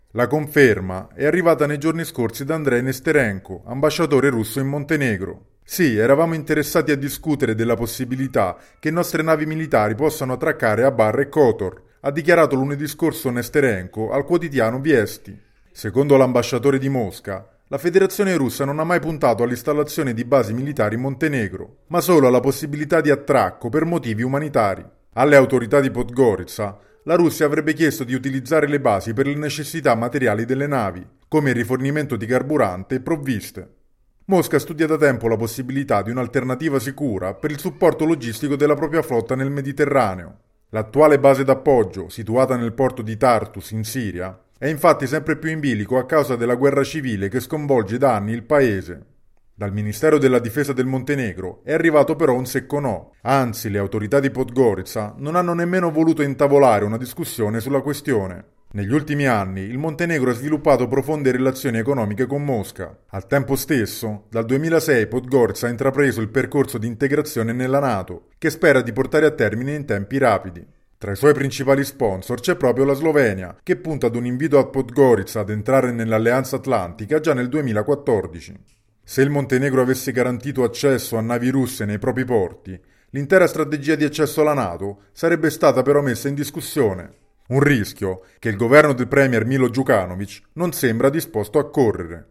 per il GR di Radio Capodistria [24 dicembre 2013]